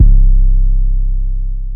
808 (Metro)_3.wav